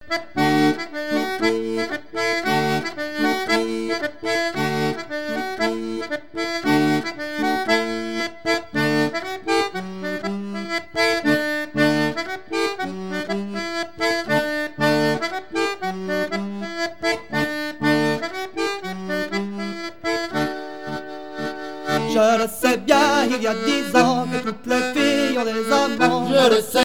gestuel : danse
Pièce musicale éditée